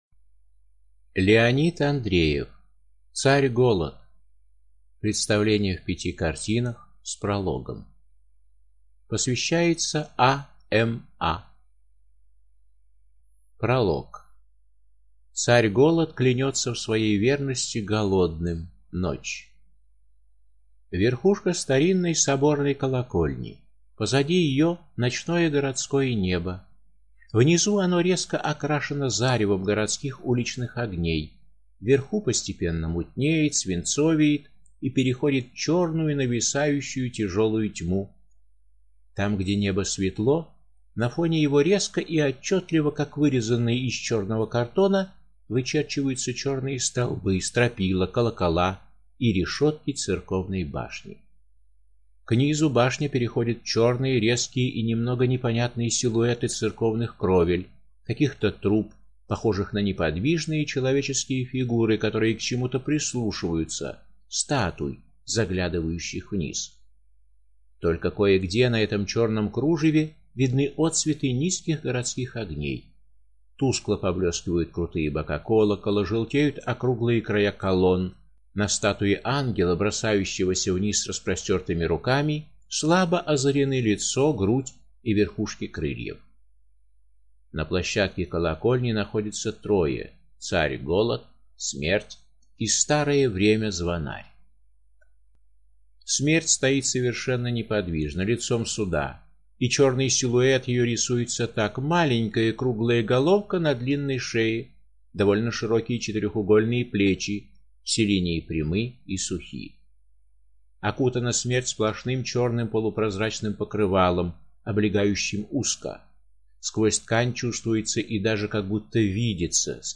Аудиокнига Царь голод | Библиотека аудиокниг